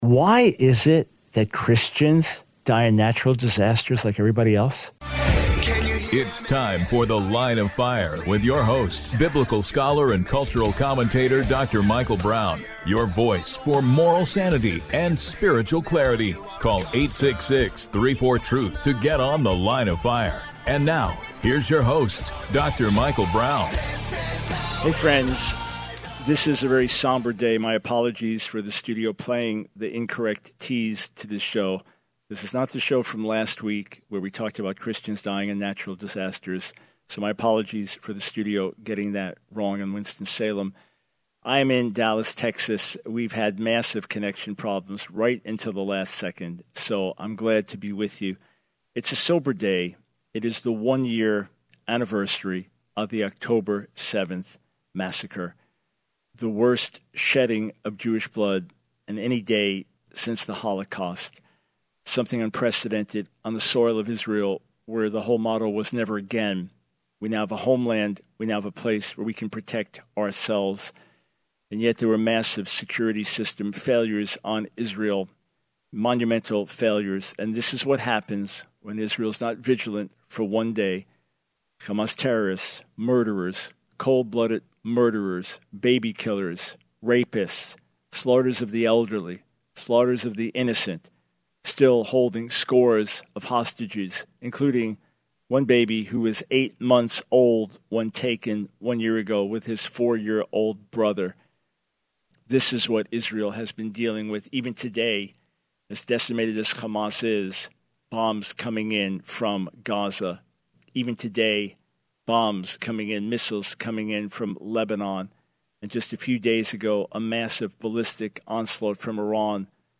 The Line of Fire Radio Broadcast for 10/07/24.